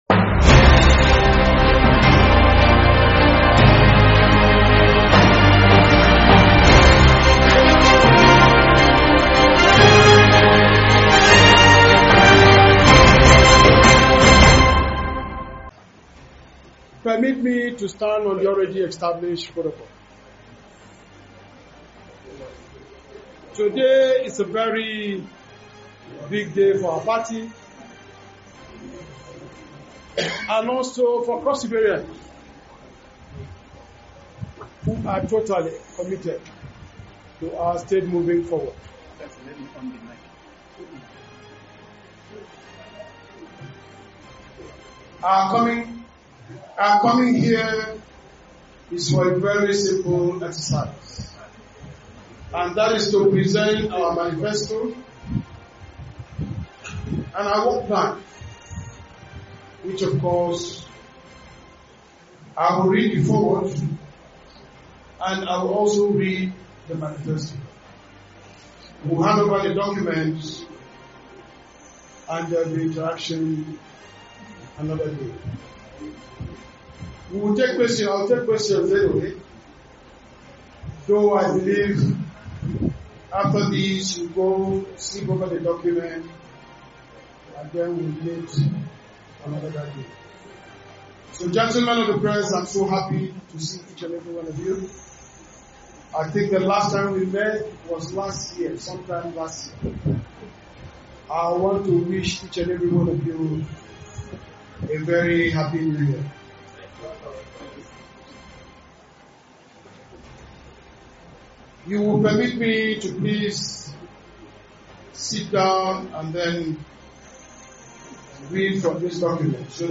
He unveiled the manifesto tagged ‘People-First’ to journalists at the NUJ press center Calabar today, Monday.